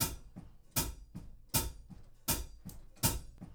GROOVE 6 05R.wav